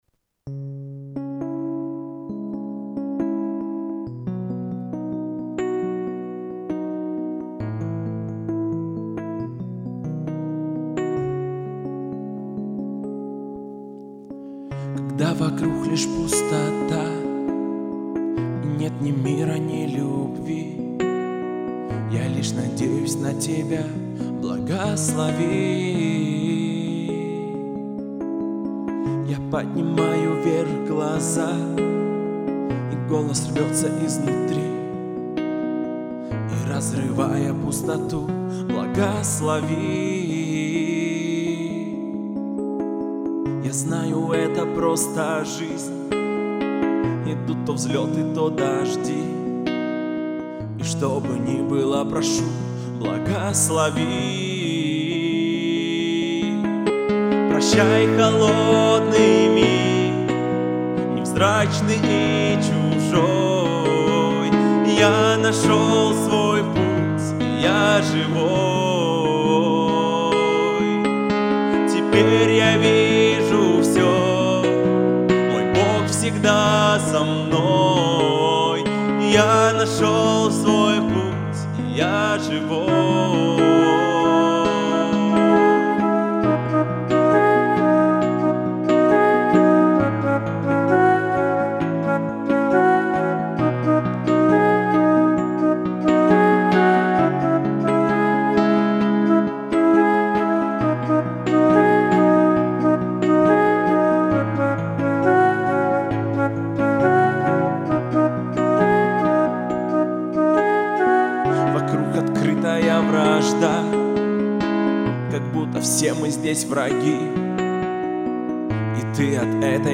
песня
354 просмотра 155 прослушиваний 16 скачиваний BPM: 67